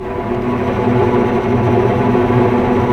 Index of /90_sSampleCDs/Roland LCDP08 Symphony Orchestra/STR_Vcs Bow FX/STR_Vcs Trem p